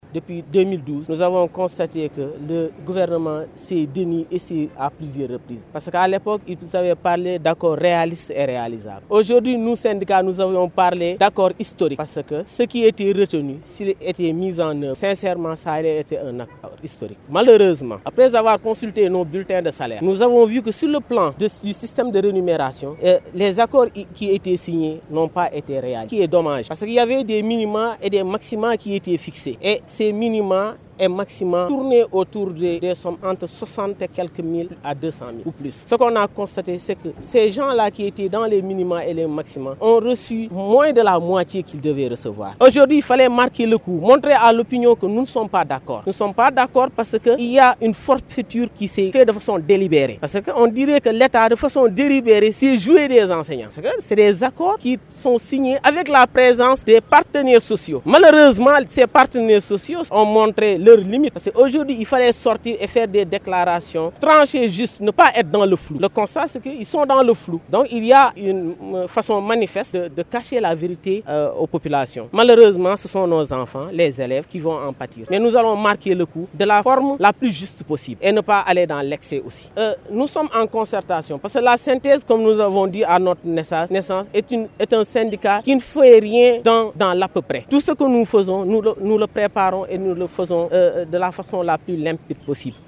Gréve des Enseignants: Au lycée Malick Sy les enseignants ont fait face à la presse